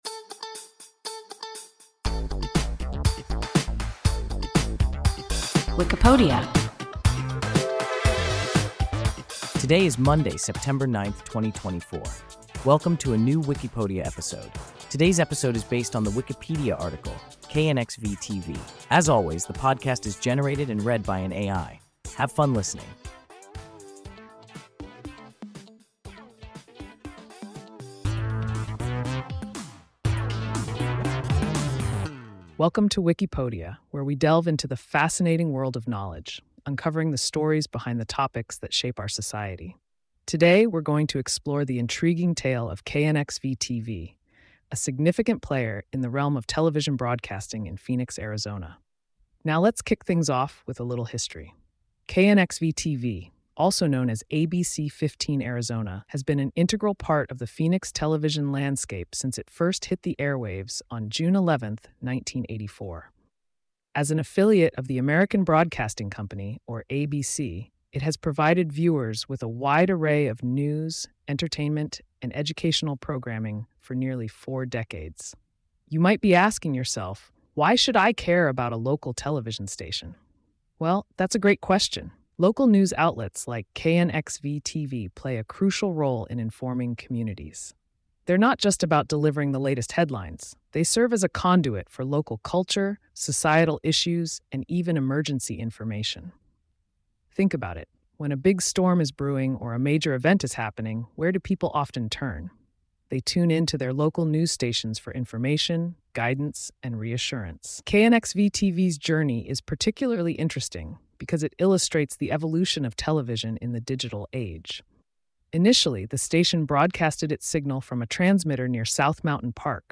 KNXV-TV – WIKIPODIA – ein KI Podcast